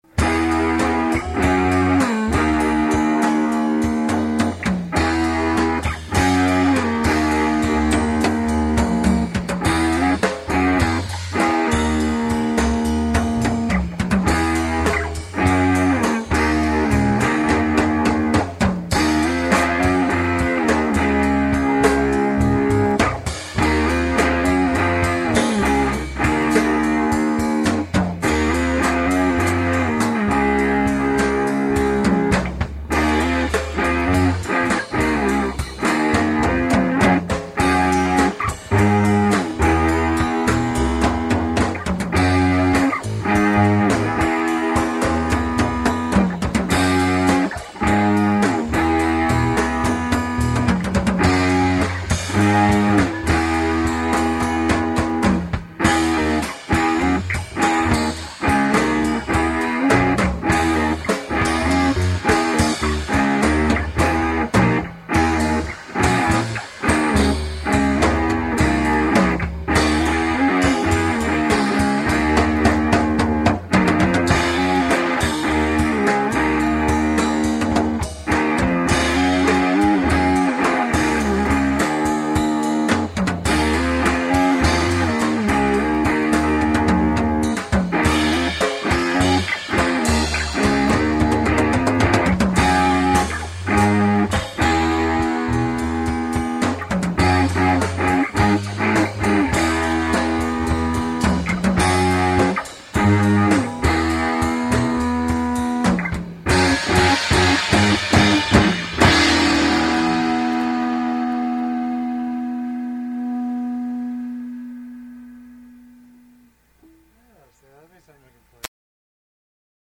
was a collection of instrumental jams